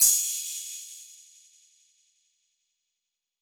MB Crash (4).wav